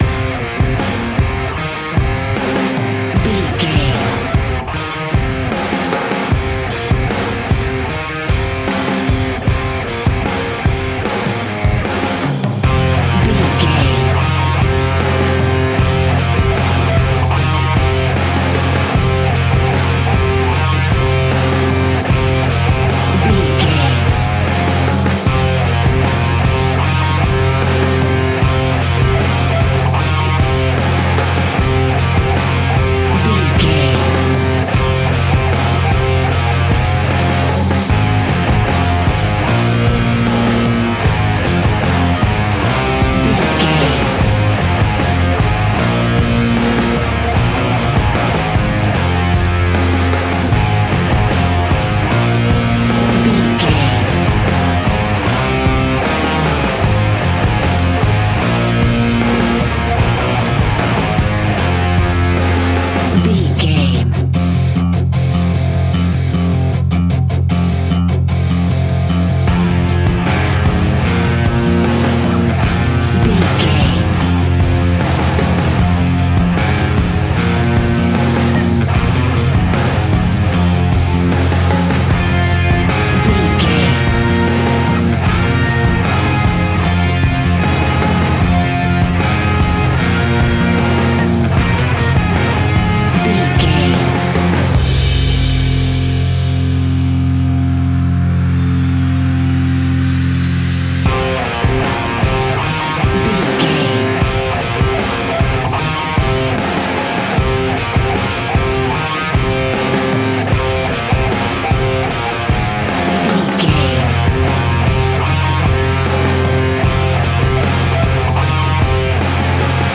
Ionian/Major
F♯
hard rock
heavy rock
distortion
instrumentals